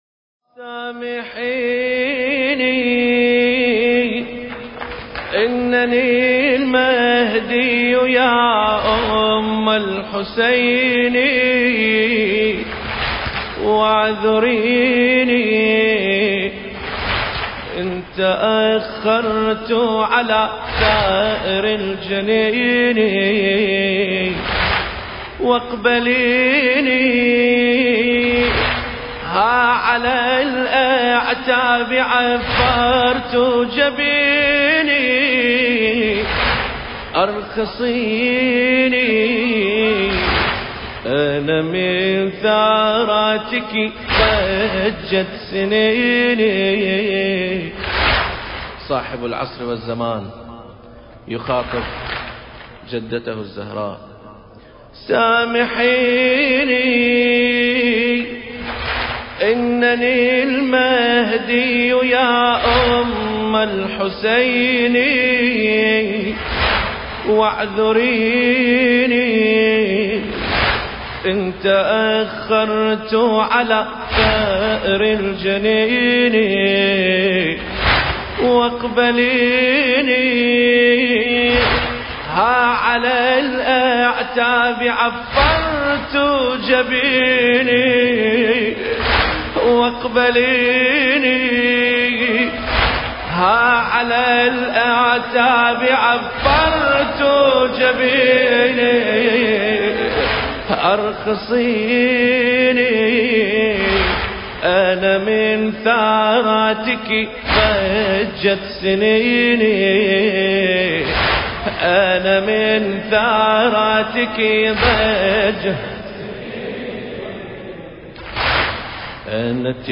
المكان: مأتم سار الكبير